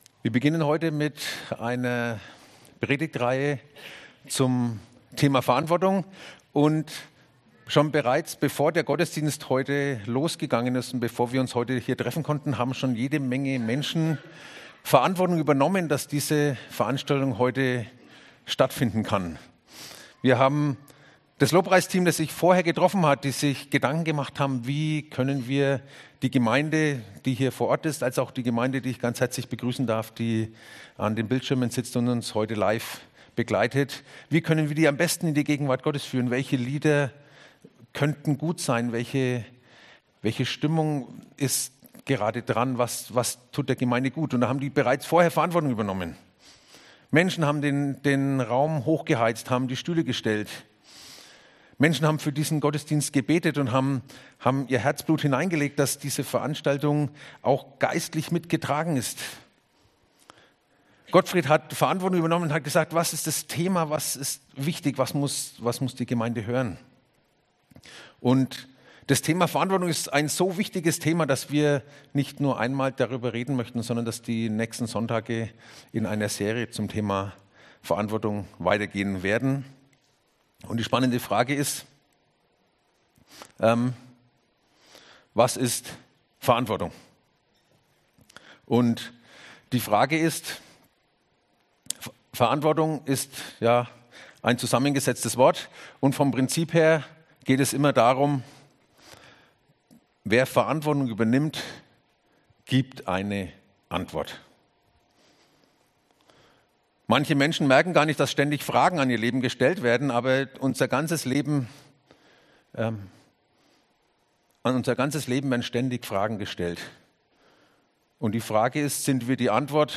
Zusammenfassung der Predigt